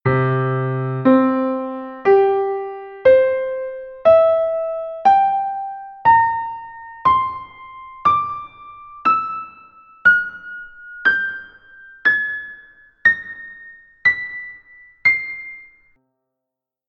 SÉRIE DE OVERTONES
harmonic-serie-overtones.mp3